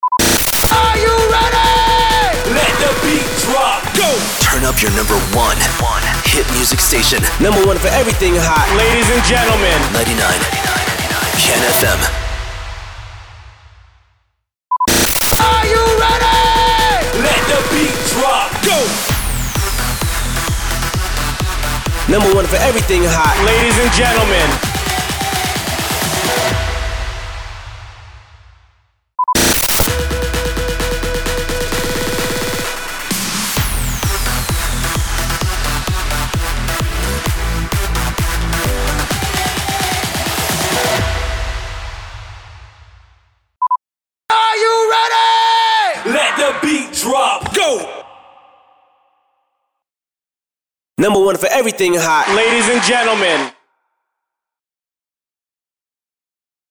340 – SWEEPER – TURN UP YOUR #1 HIT MUSIC STATION